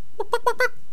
chicken_select2.wav